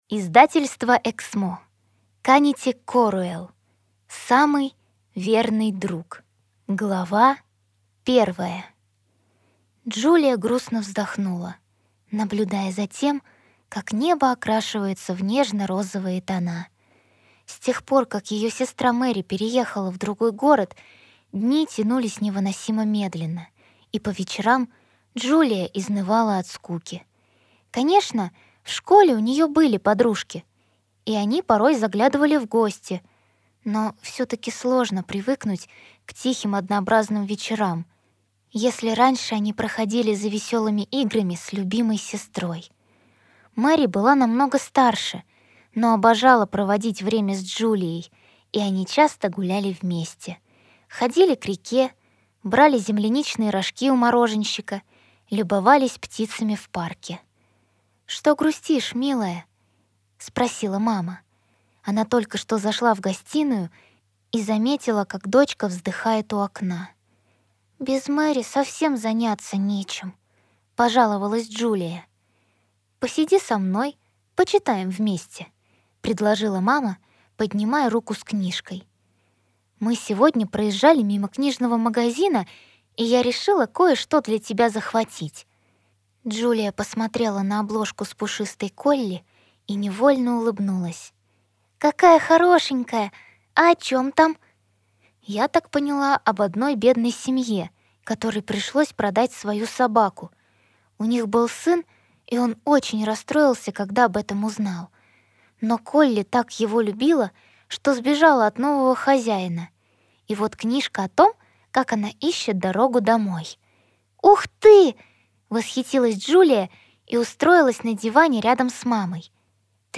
Аудиокнига Самый верный друг | Библиотека аудиокниг